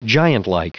Prononciation du mot giantlike en anglais (fichier audio)
Prononciation du mot : giantlike